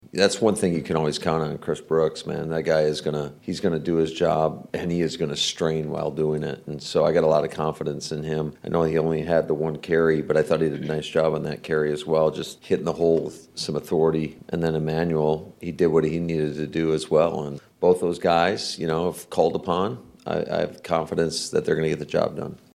(LEARFIELD) – There was some good news coming out of Packers coach Matt LaFleur’s meeting with the media on Monday.